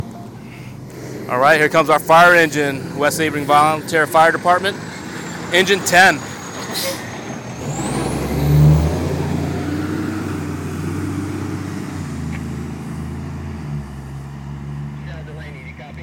fire engine arrives